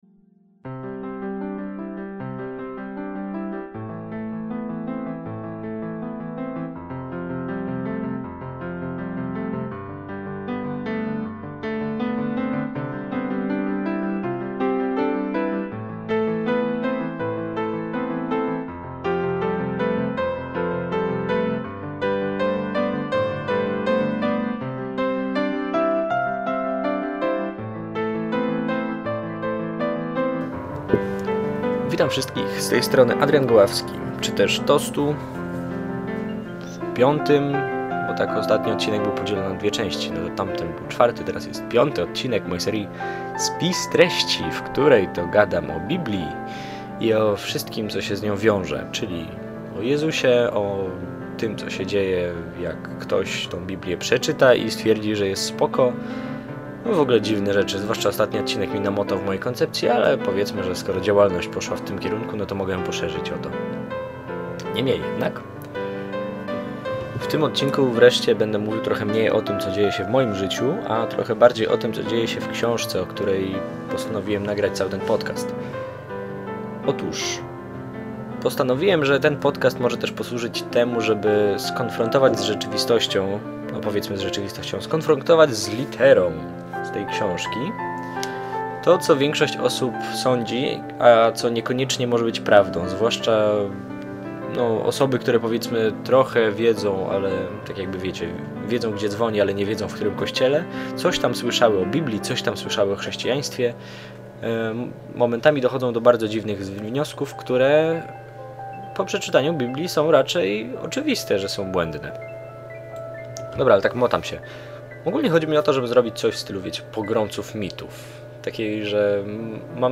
Swobodne gadanie o Bogu prostym, nieteologicznym językiem.